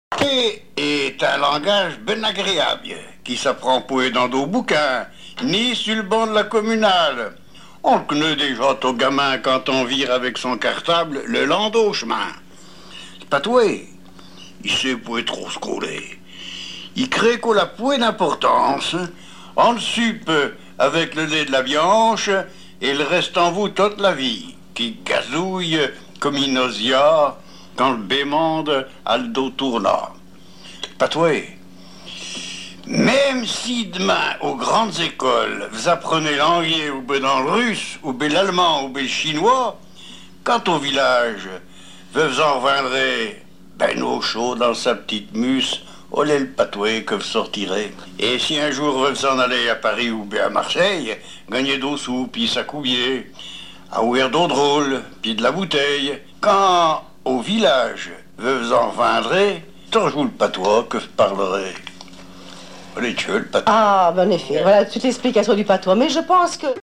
Langue Patois local
Genre poésie
émission La fin de la Rabinaïe sur Alouette